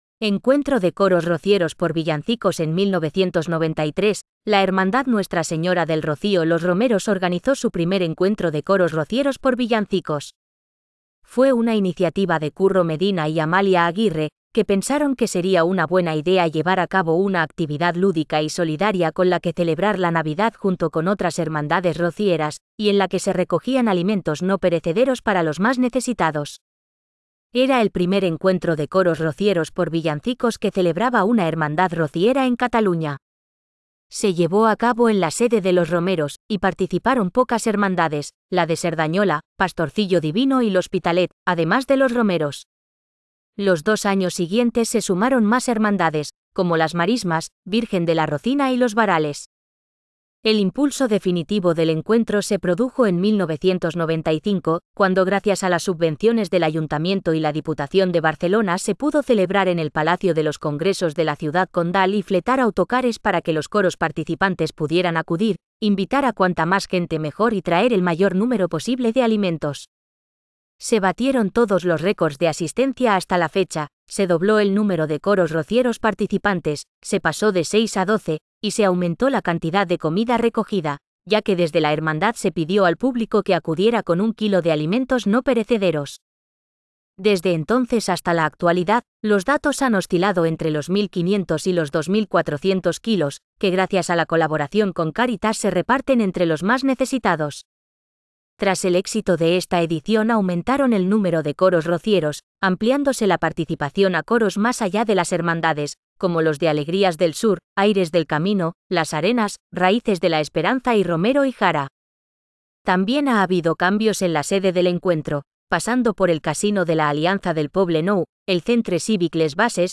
Encuentro de Coros Rocieros por Villancicos. 2014.
Encuentro-de-Coros-R.m4a